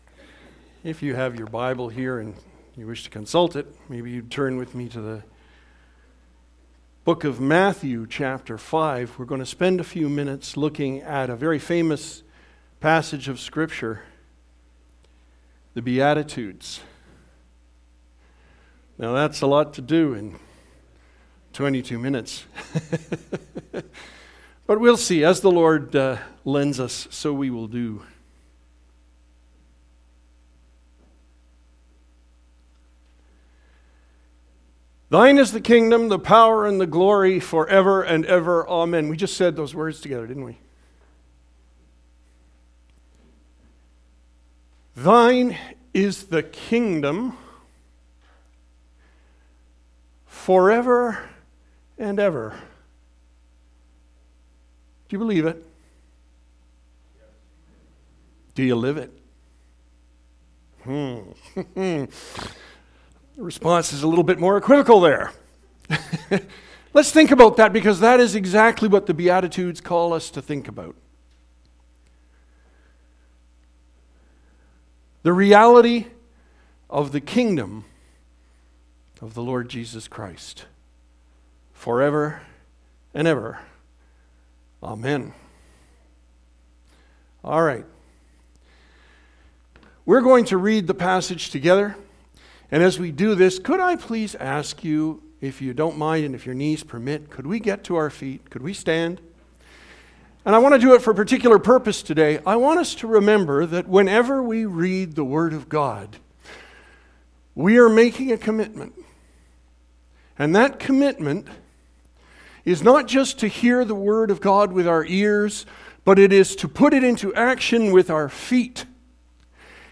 Sermon Audio and Video Surprising Reversals: Who is Really Blessed?"